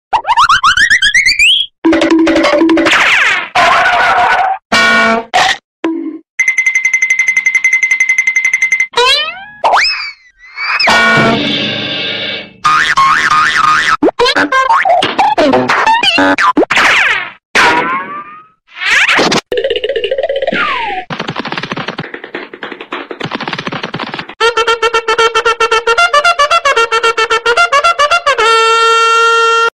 Category Sound Effects